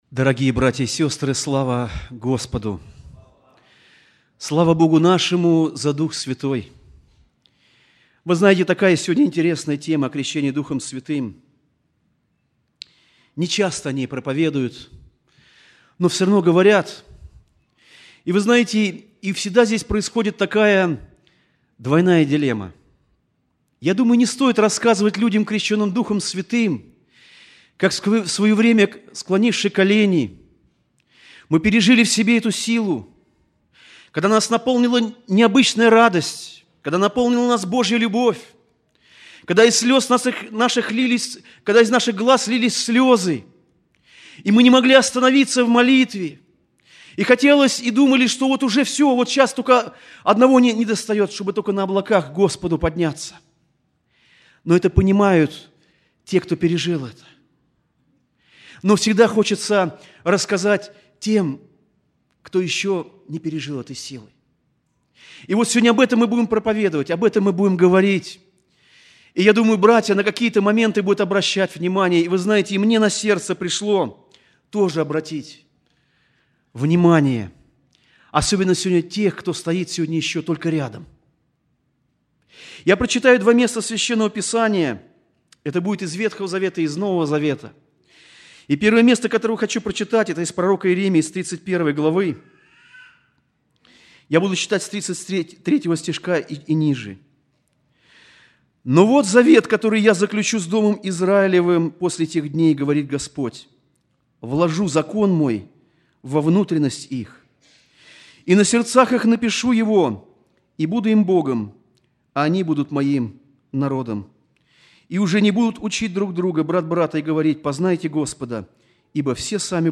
02+Проповедь.mp3